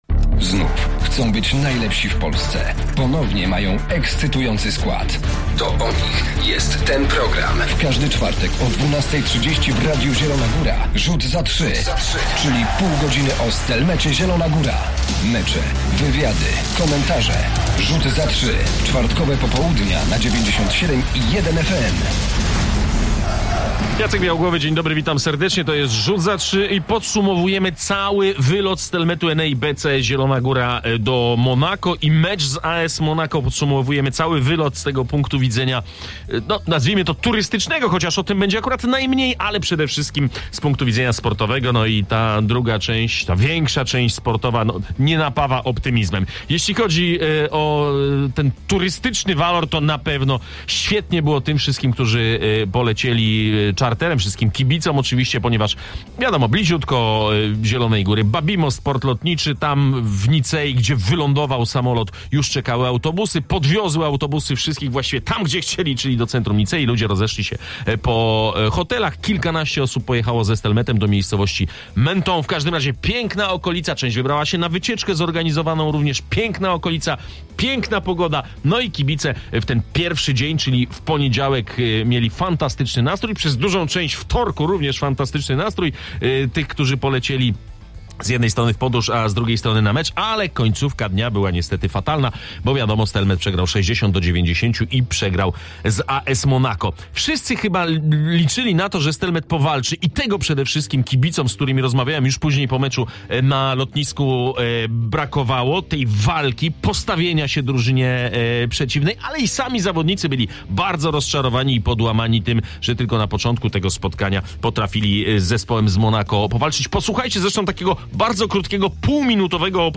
Zapraszamy na magazyn koszykarski Rzut za trzy.